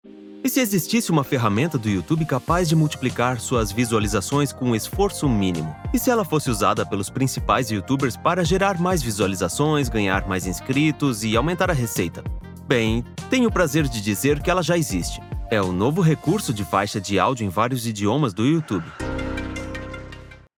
Vídeos explicativos
Neumann TLM193 Microphone
Acoustic and soundproof Booth
BarítonoBajo